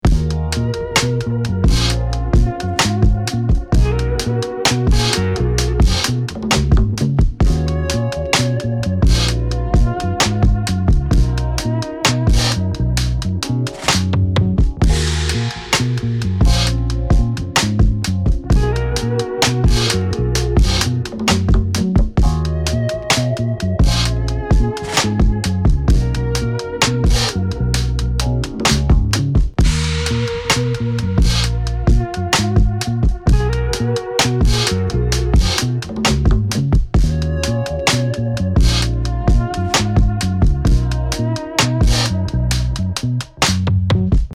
royalty-free drum breaks, percussion loops and one-shots
Explosive Drum Sounds